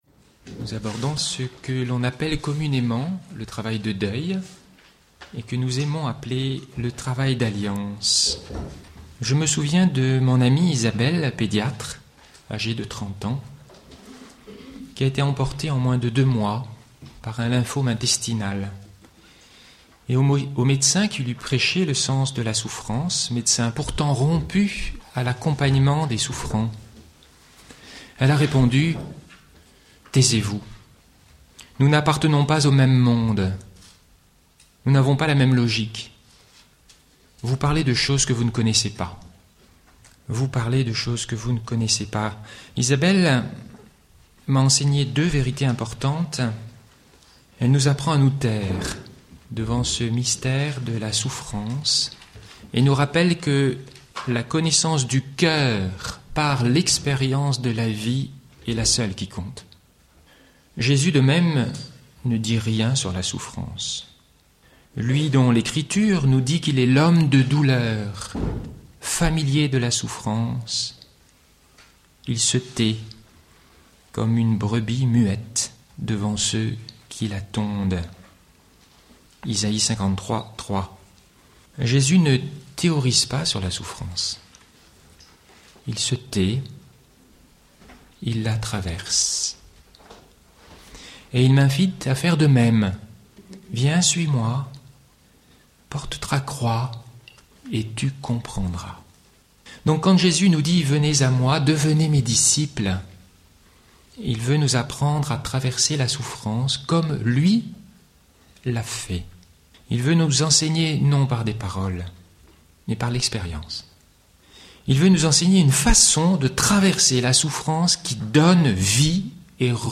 Enseignement
Enregistré au Puy en Velay en 2007